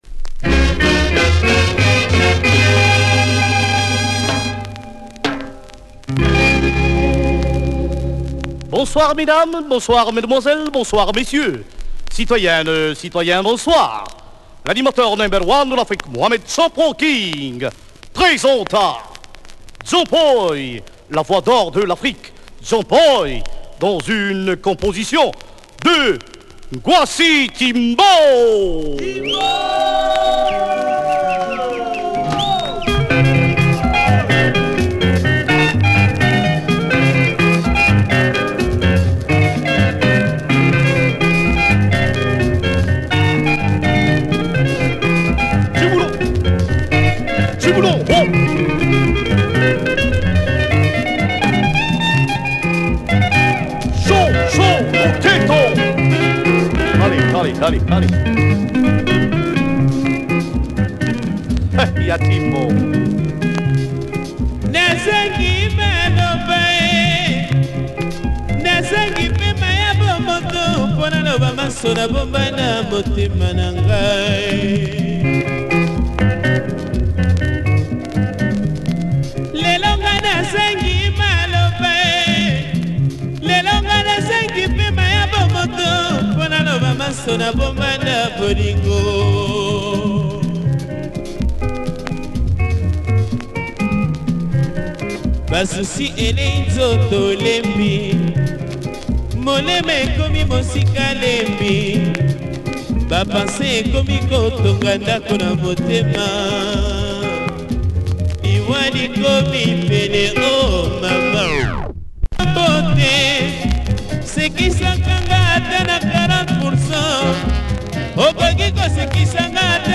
Nice introduction spoken in French.